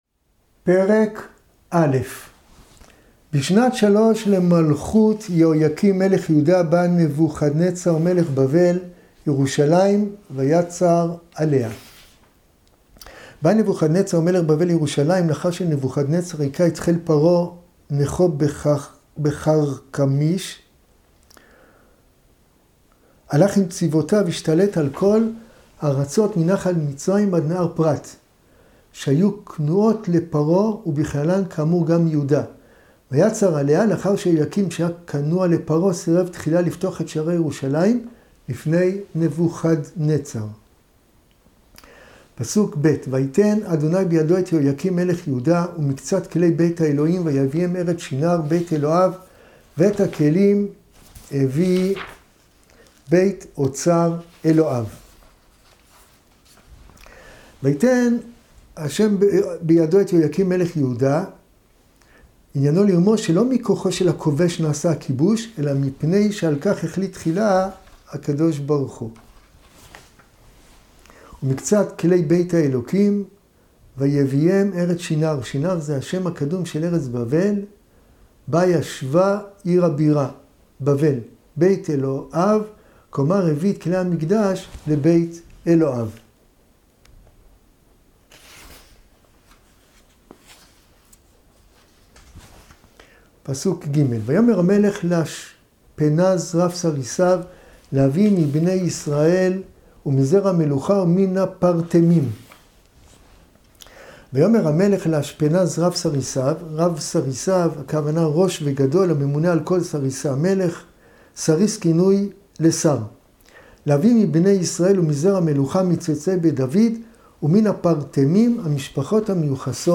שיעורים בתנ"ך - ספר דניאל